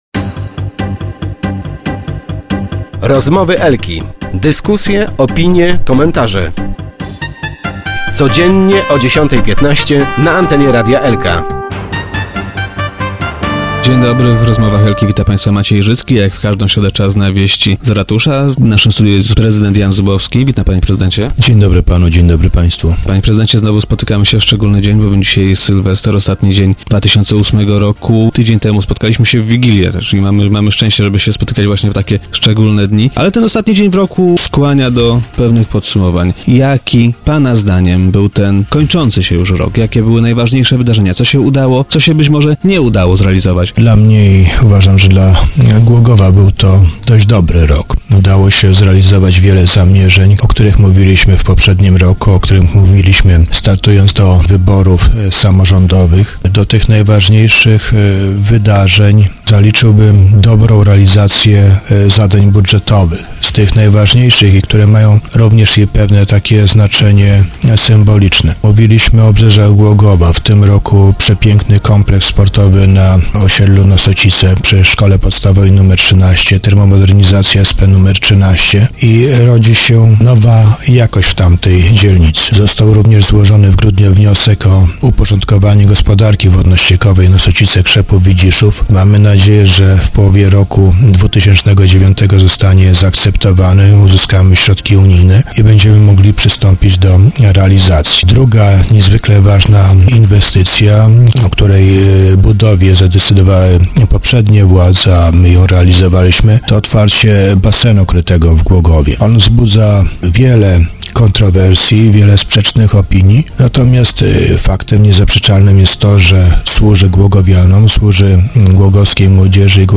Głogów. - Myślę, że to nie był zły rok dla Głogowa i jego mieszkańców - powiedział dziś na antenie Radia Elka, prezydent Jan Zubowski. Gospodarz miasta podsumował kończący się dziś 2008 rok.